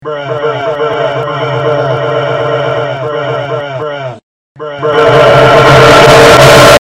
bruh spam